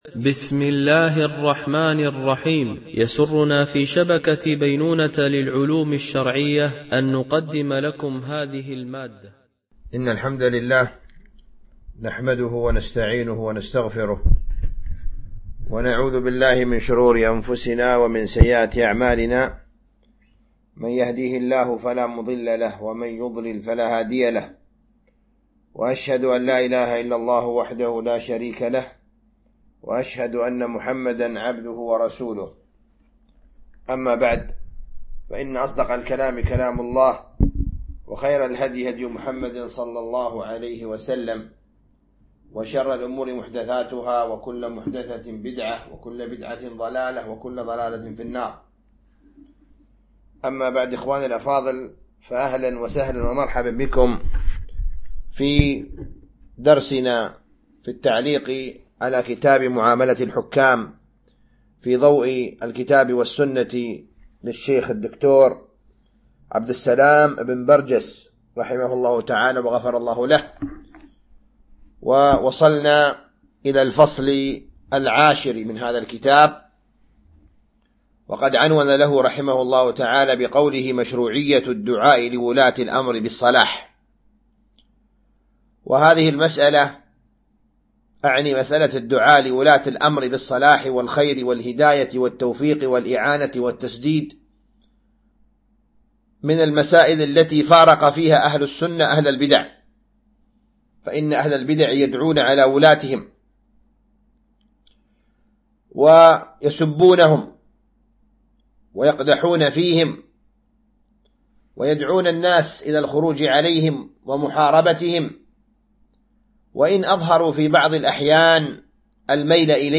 التعليق على كتاب: معاملة الحكام في ضوء الكتاب والسنة - الدرس 19 والأخير (الدعاء لولي الأمر)
MP3 Mono 22kHz 32Kbps (CBR)